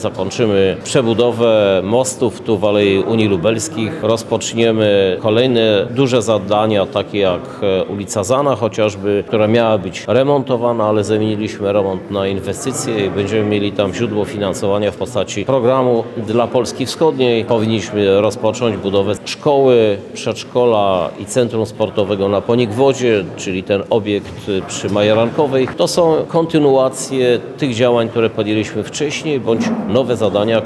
– To pozwoli na stabilny rozwój miasta – mówi prezydent miasta, Krzysztof Żuk.